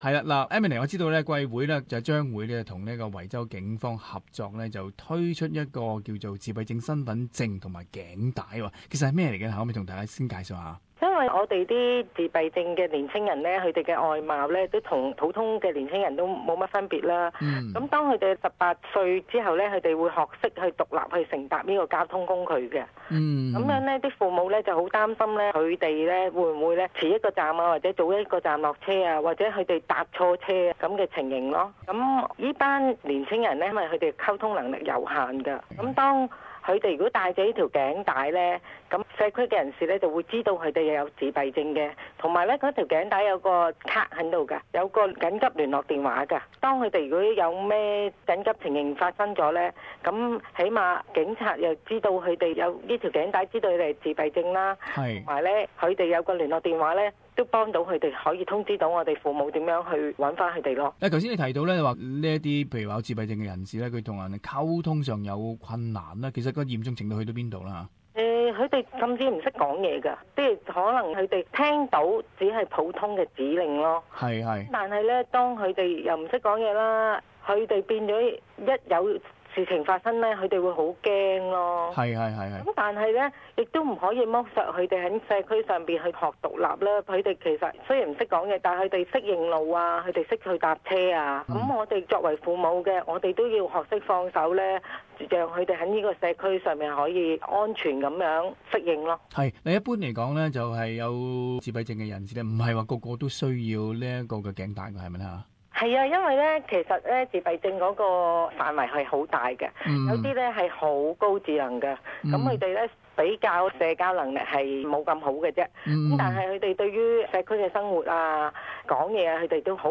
【社团专访】一个为弱智及自闭症儿童而设的身份卡